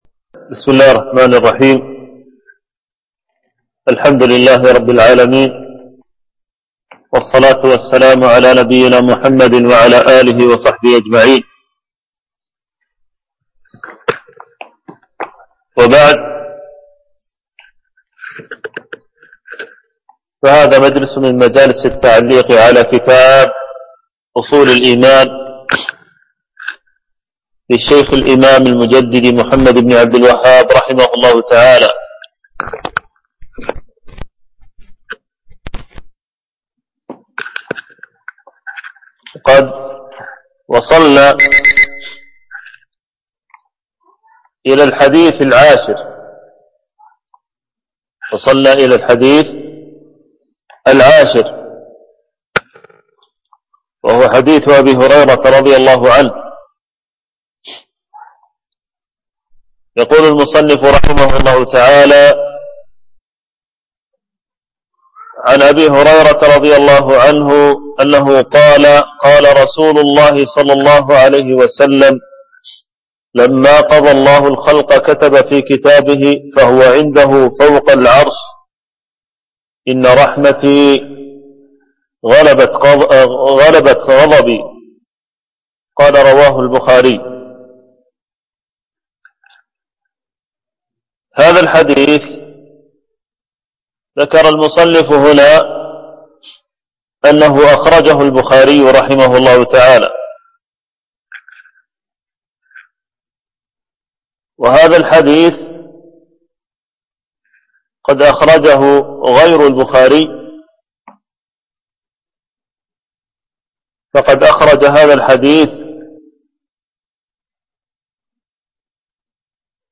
التنسيق: MP3 Mono 22kHz 40Kbps (CBR)